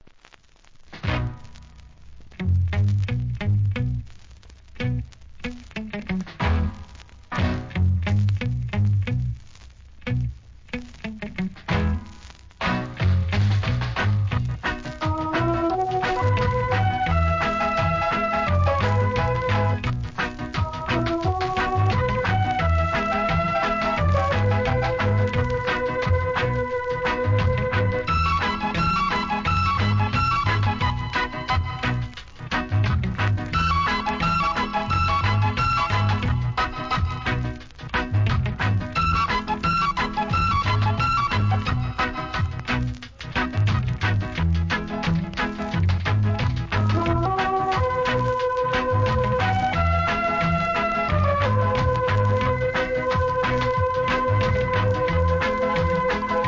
REGGAE
1969年の名作オルガンINST.!!!